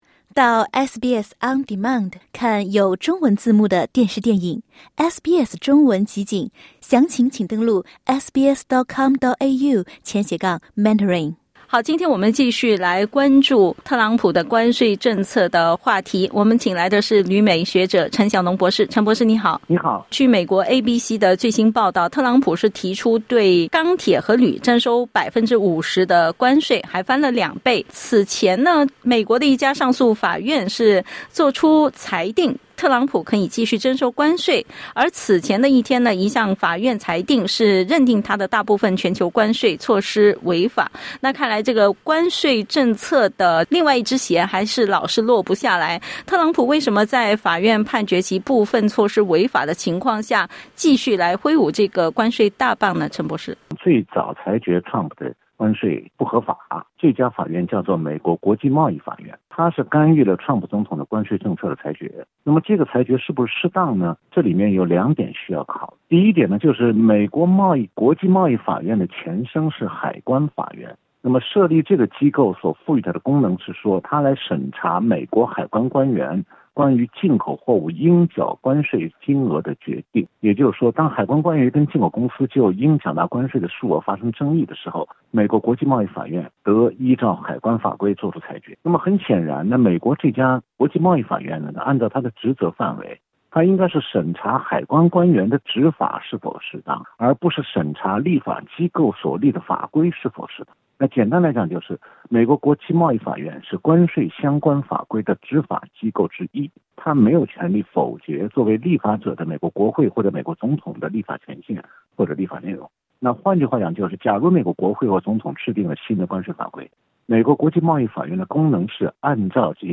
点击音频收听详细内容 采访内容仅为嘉宾观点 欢迎下载应用程序SBS Audio，订阅Mandarin。